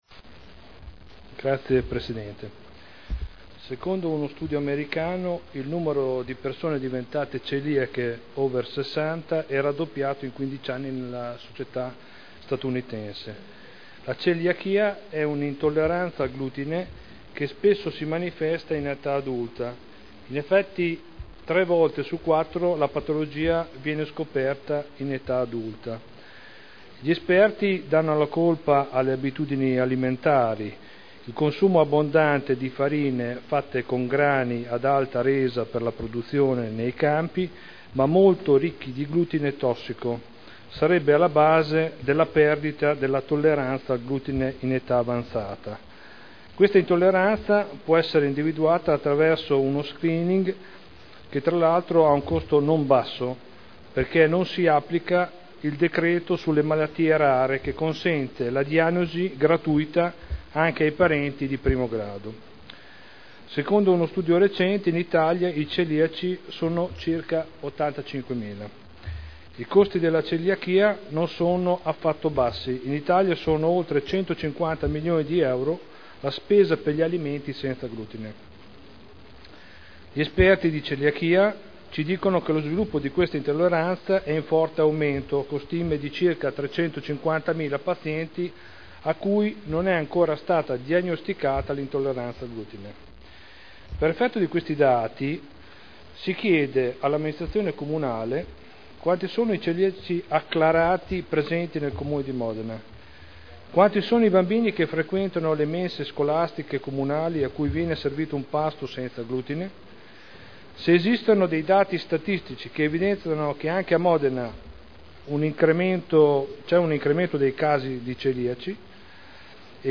Seduta del 10/01/2011. Interrogazione del consigliere Prampolini (P.D.) avente per oggetto: “Celiachia a Modena”